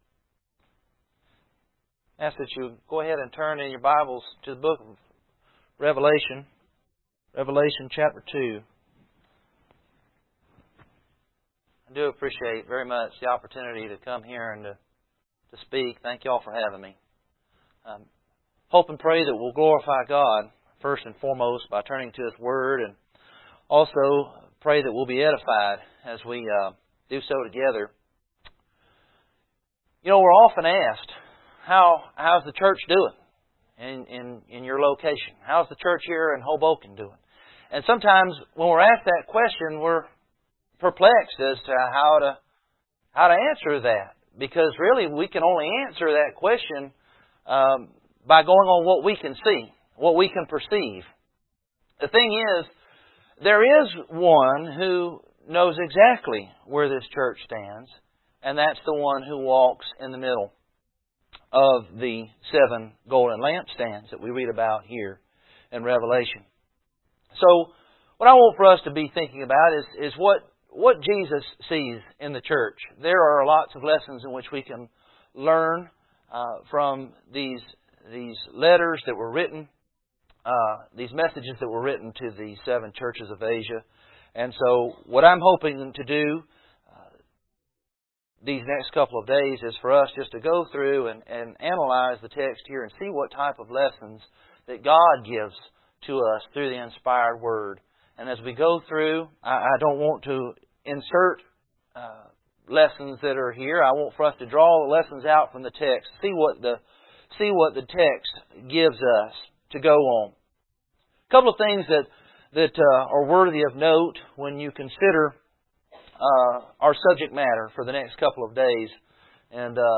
Passage: Revelation 2:1-7 Service Type: Gospel Meeting We did not get a recording of this lesson when it was preached here at Oak Grove.